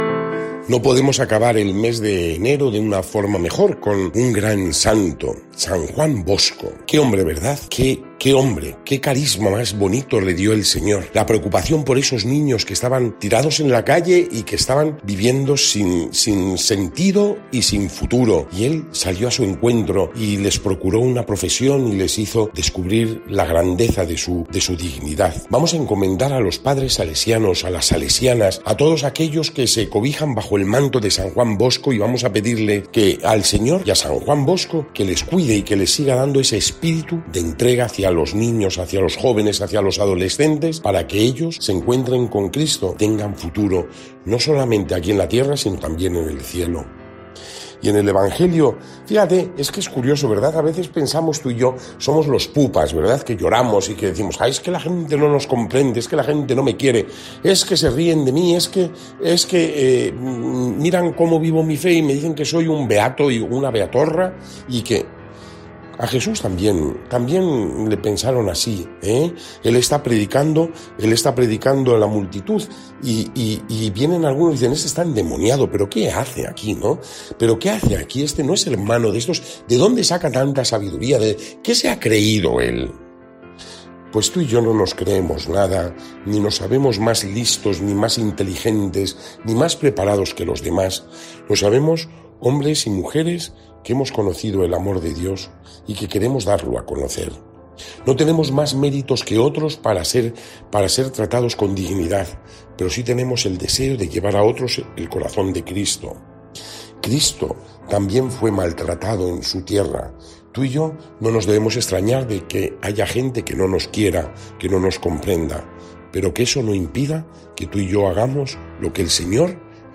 Lectura del santo evangelio según san Marcos 6, 1-6